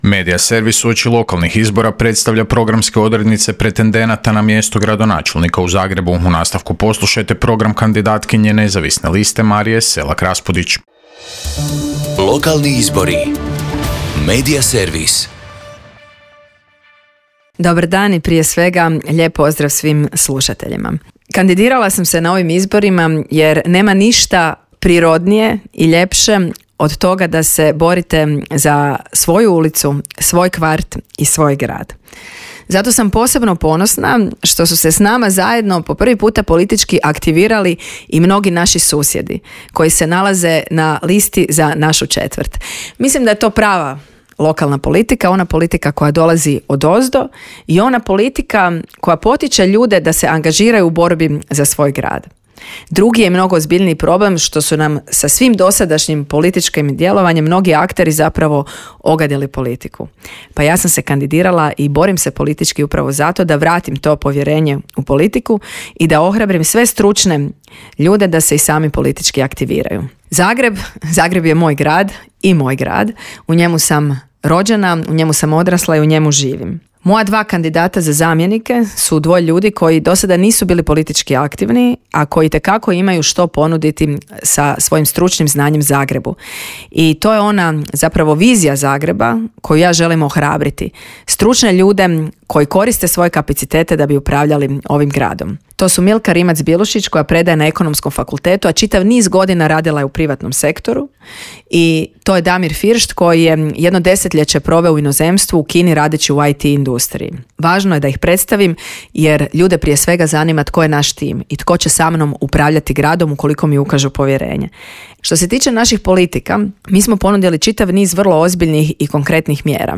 ZAGREB - Uoči lokalnih izbora kandidati za gradonačelnicu/gradonačelnika predstavljaju na Media servisu svoje programe u trajanju od pet minuta. Kandidatkinja Nezavisne liste za gradonačelnicu Zagreba Marija Selak Raspudić predstavila je građanima svoj program koji prenosimo u nastavku.